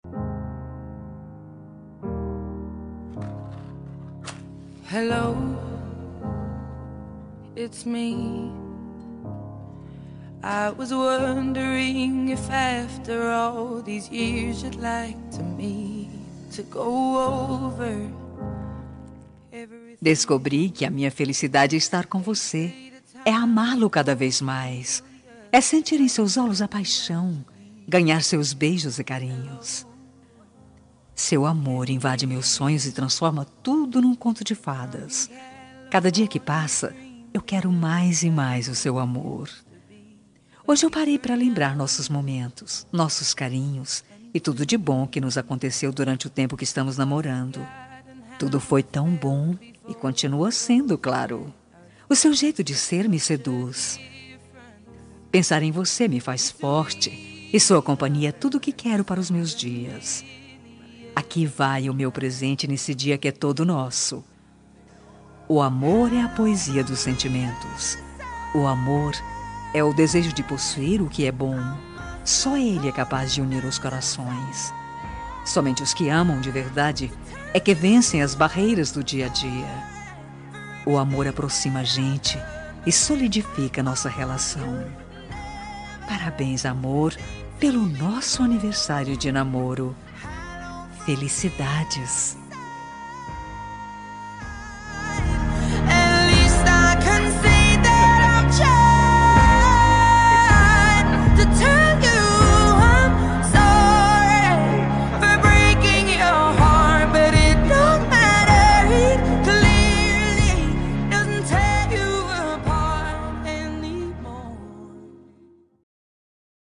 Telemensagem Aniversário de Namoro – Voz Feminina – Cód: 8098 – Linda.